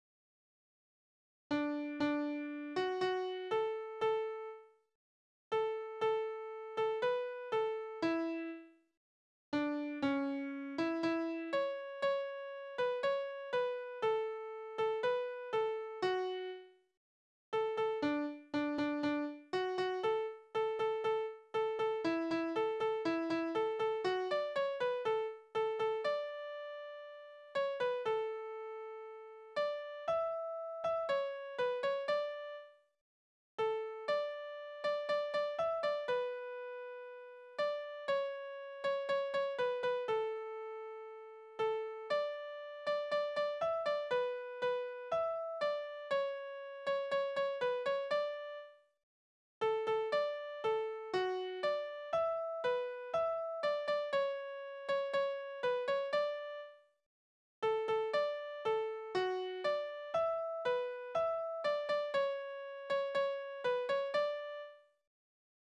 Heimatlieder: Das schöne Tirolerland
Tonart: D-Dur
Taktart: 4/4
Tonumfang: große Dezime
Besetzung: vokal